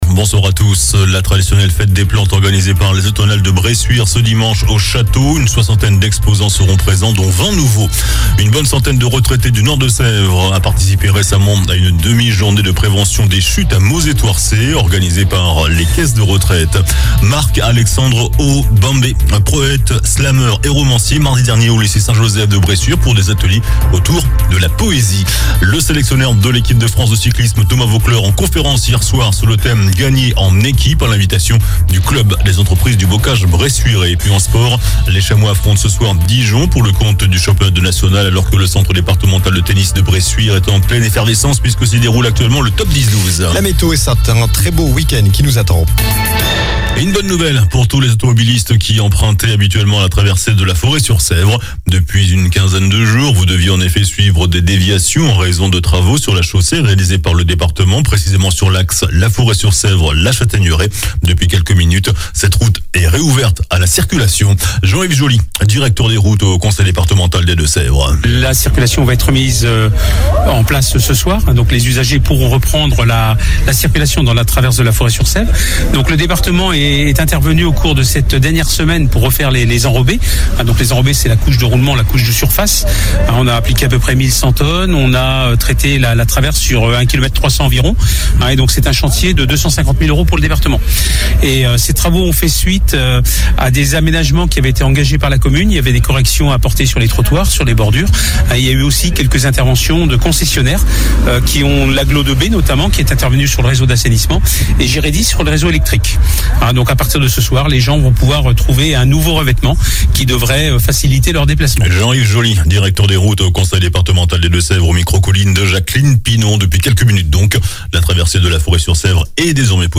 Journal du vendredi 29 septembre (soir)